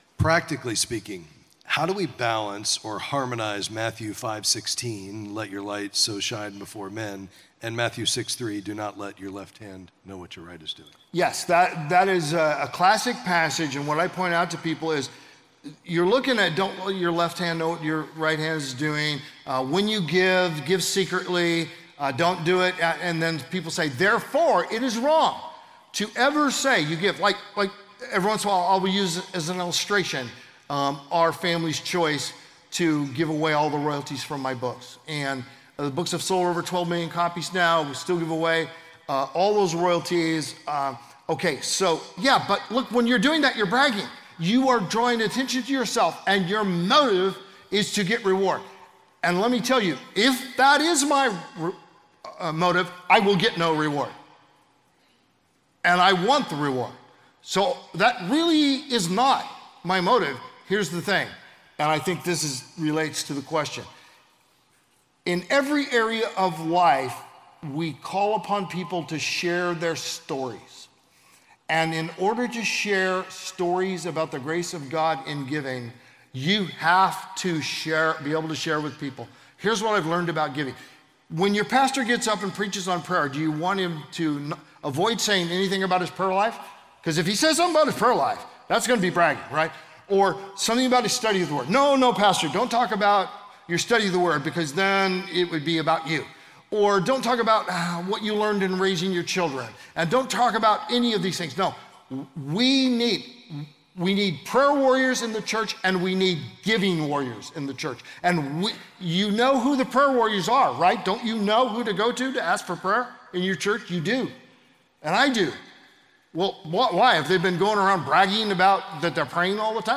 In this clip from a Q&A at the Kingdom Advisors Conference